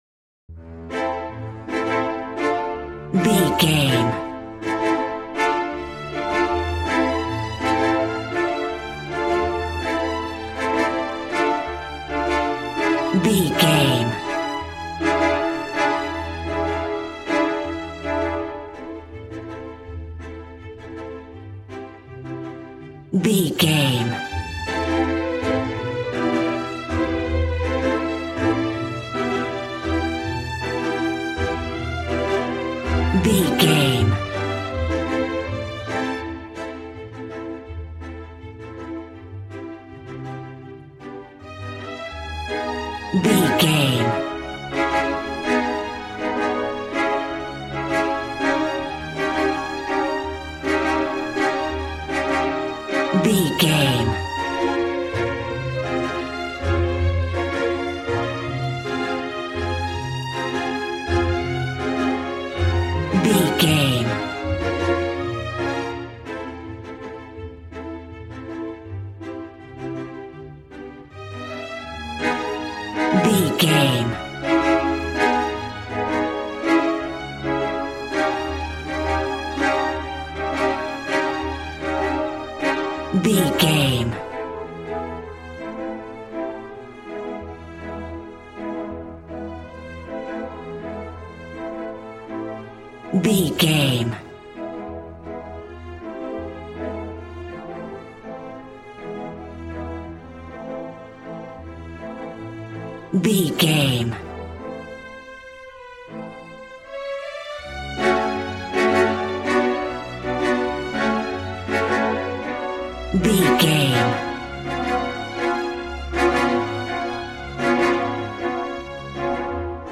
Ionian/Major
brass
strings
violin
regal